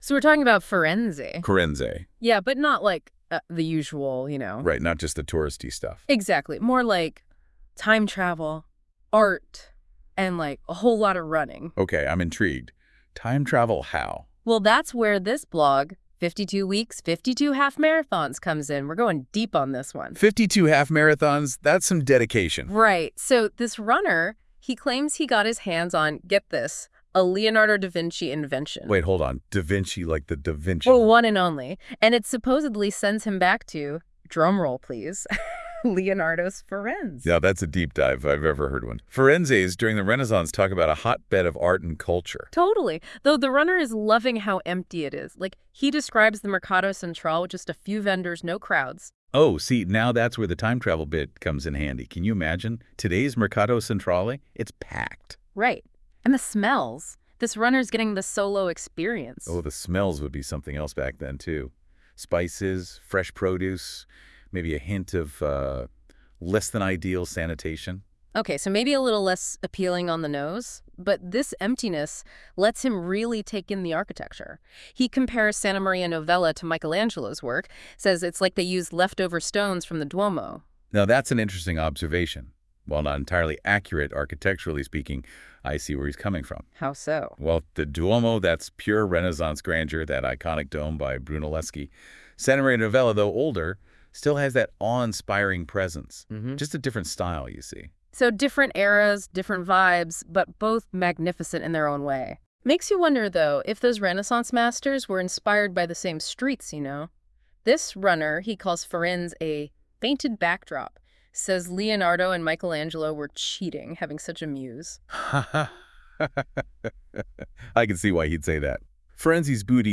• Move Google AI Generated podcasts